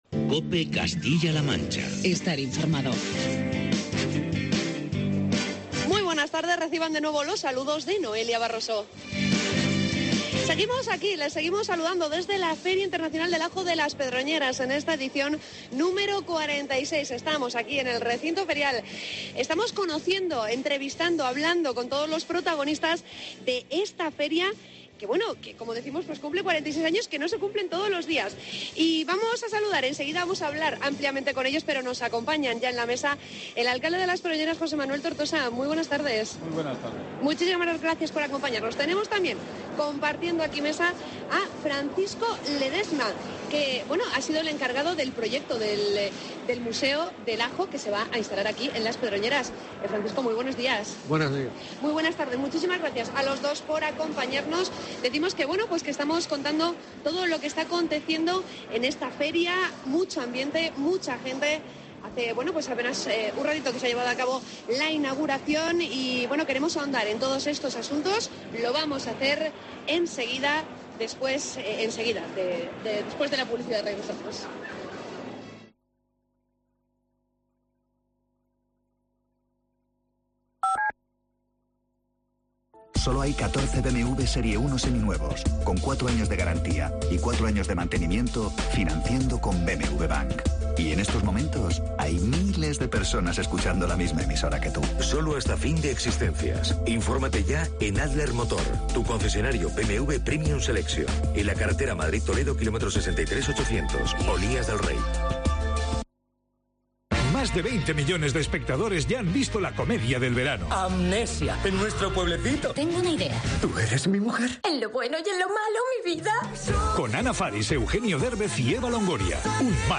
Progrmaa regional desde la Feria Internacional del Ajo de Las Pedroñeras 12.50
Entrevista